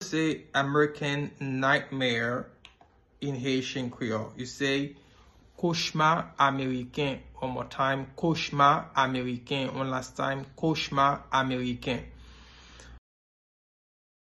Pronunciation:
2.How-to-say-American-Nightmare-in-Haitian-Creole-–-Kochma-Ameriken-pronunciation.mp3